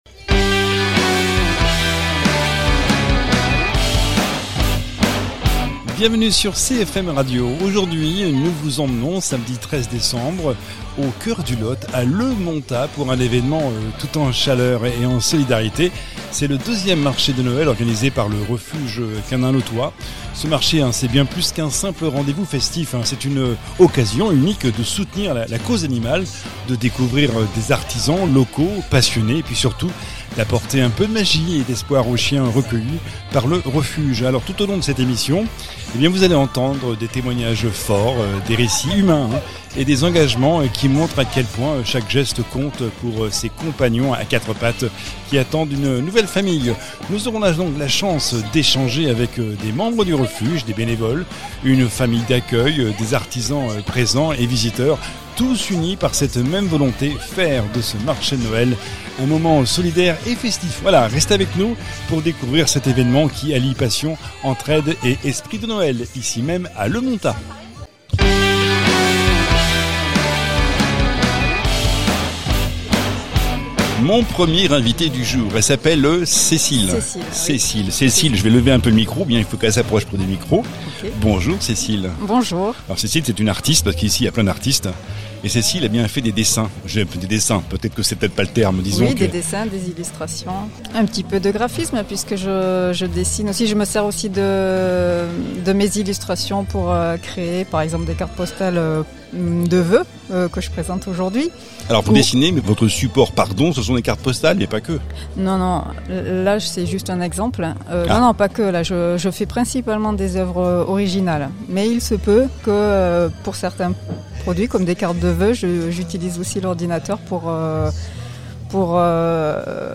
Samedi 13 décembre 2025 à Le Montat (Lot), le 2? Marché de Noël du Refuge Canin Lotois s’est tenu à la salle des fêtes.. Artisans, gourmandises, idées cadeaux et surtout une belle cause : soutenir les chiens du refuge et les bénévoles qui s’en occupent toute l’année.